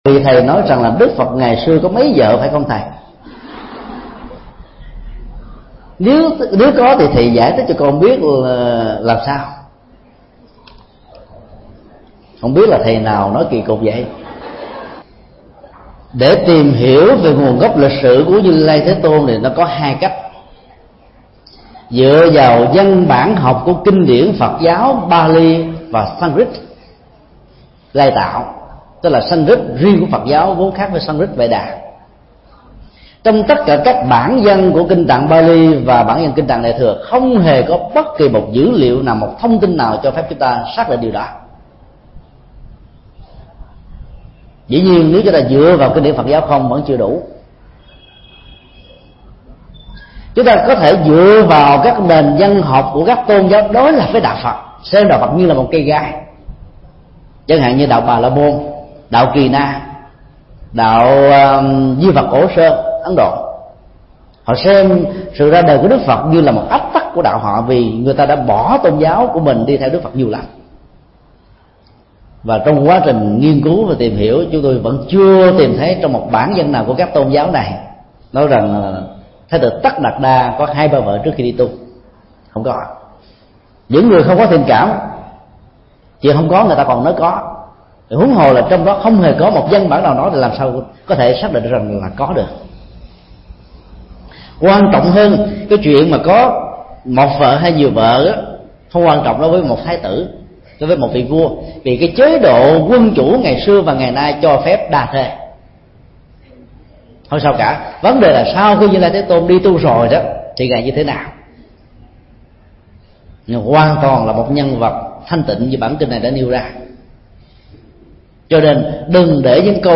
Vấn đáp: Nguồn gốc, lịch sự Đức Phật